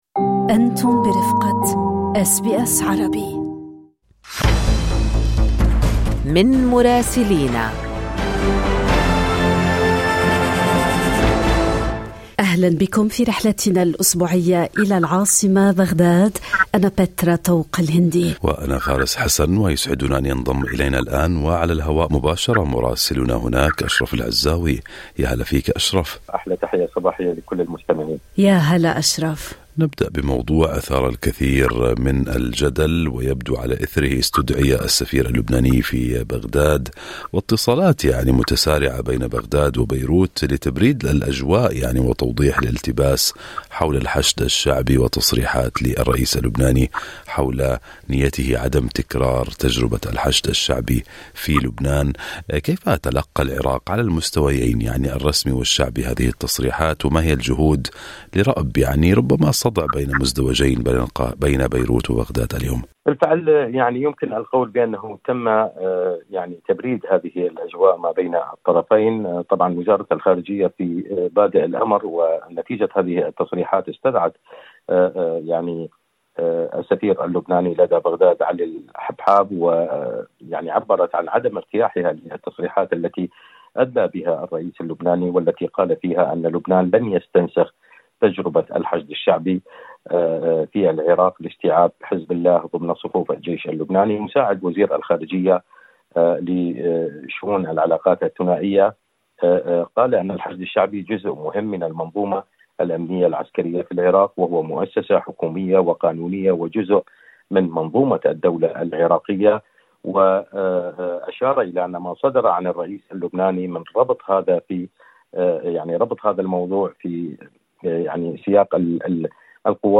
بغداد غاضبة من بيروت، السوداني يلتقي الشرع وأخبار أخرى من مراسلنا في العراق